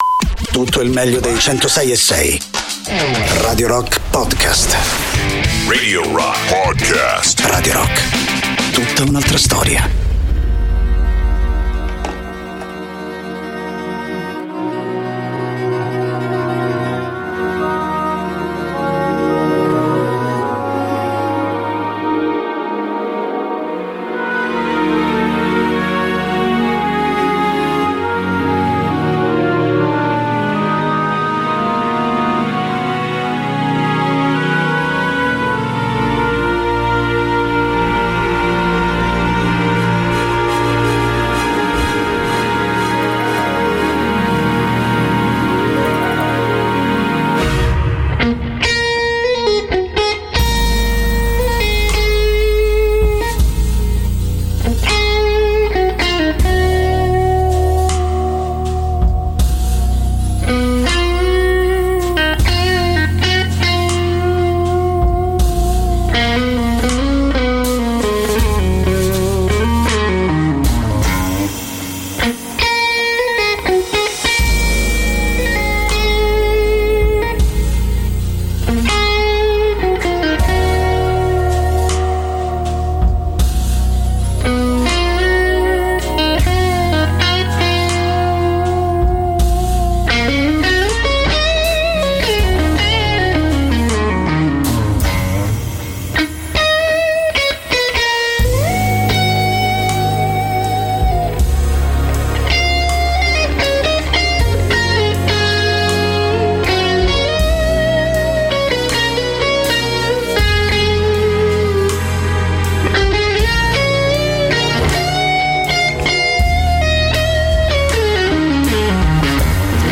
Interviste: Alex Britti (27-09-22)